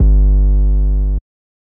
GOOD AM 808 2.wav